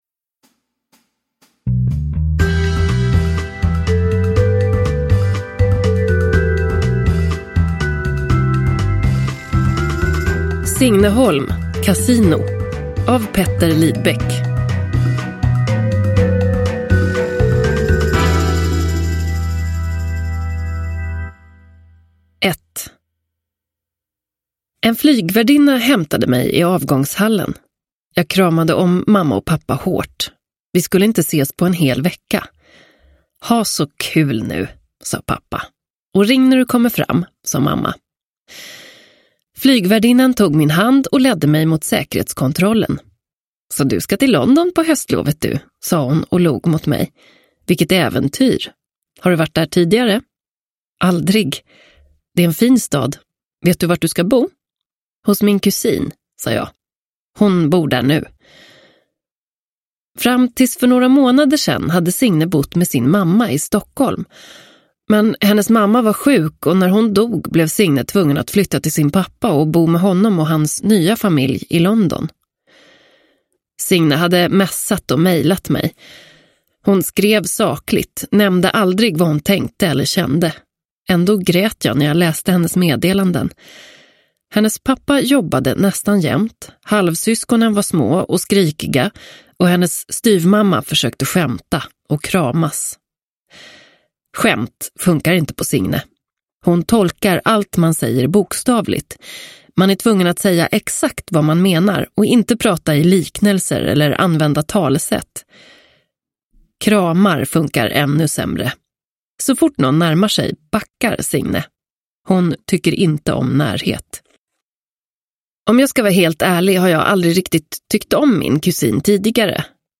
Casino – Ljudbok – Laddas ner